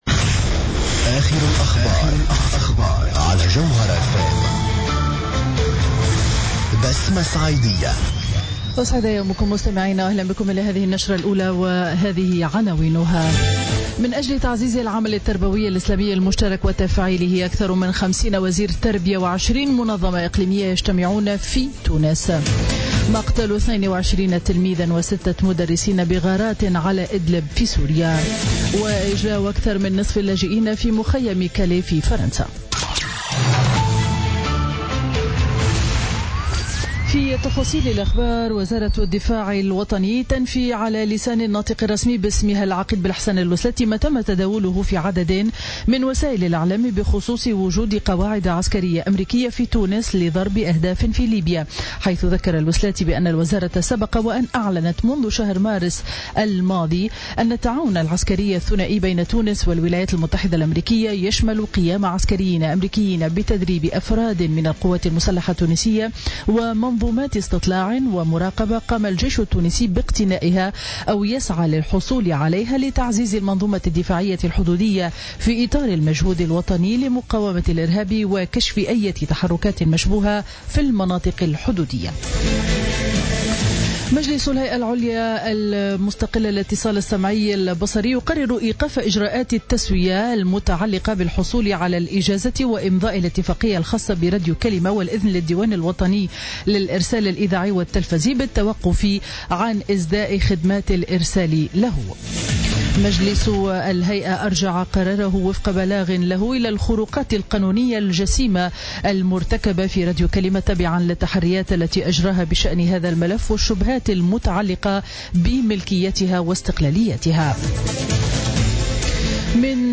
Journal Info 07h00 du jeudi 27 octobre 2016